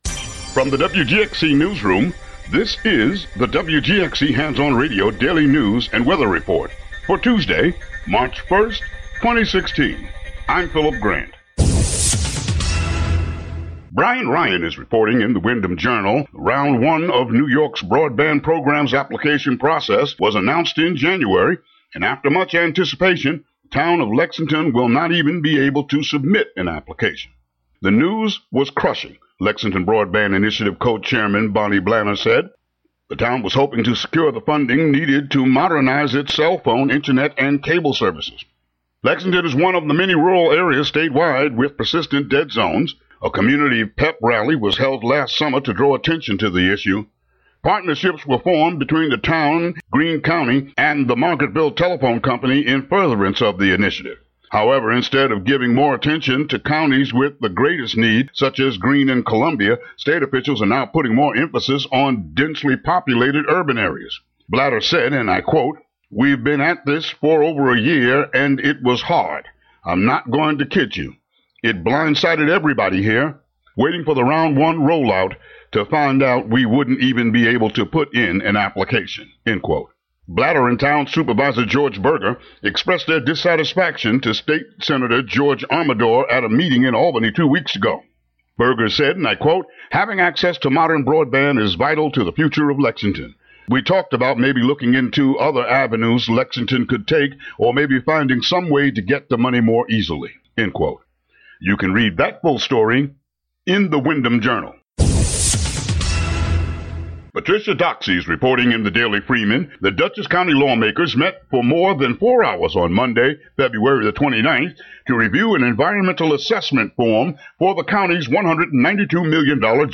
Local headlines and weather for Tue., Mar. 1.